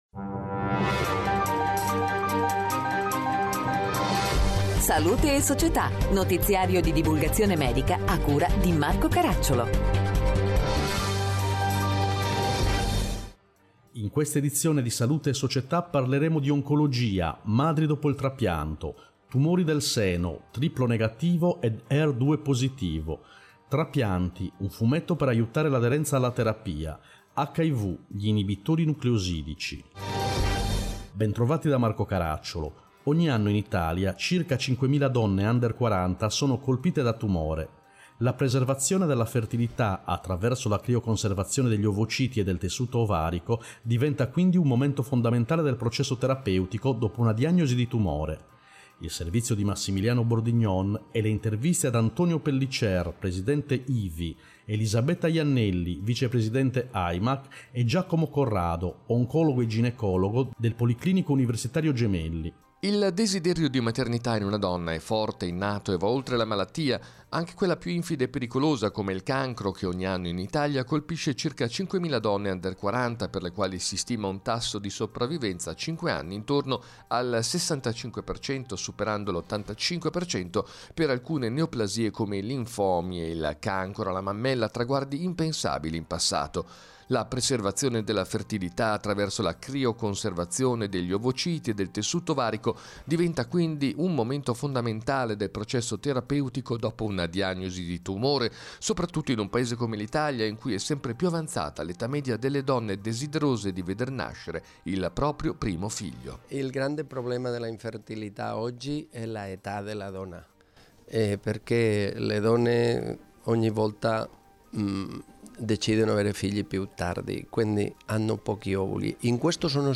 In questa edizione: 1. Oncologia, Madri dopo il trapianto 2. Tumori del seno, Triplo negativo ed Her 2 positivo 3. Trapianti, Un fumetto per aiutare l’aderenza alla terapia 4. Hiv, Ibitori nucleosidici Interviste